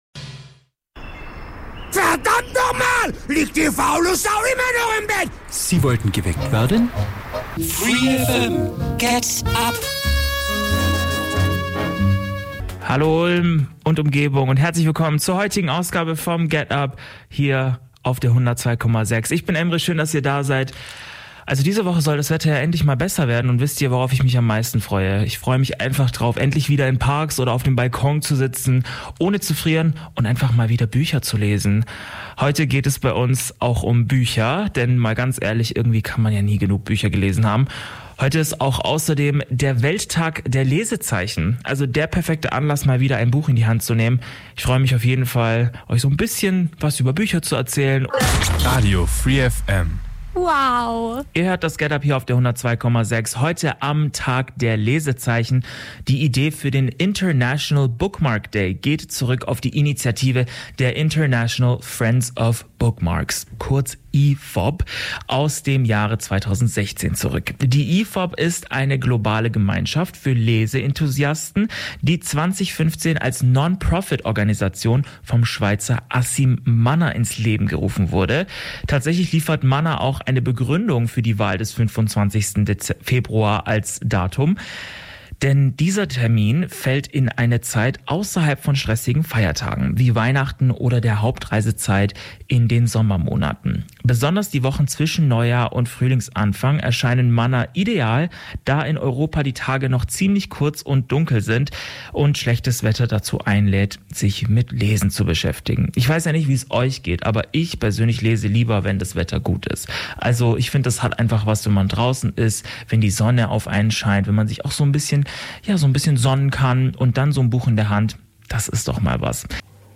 Aber funktioniert es auch, wenn nur noch vier Tage gearbeitet wird? Radio free FM hat auf den Straßen Ulms nachgefragt, was die Menschen über ein Arbeitsmodell mit vier Tagen die Woche denken.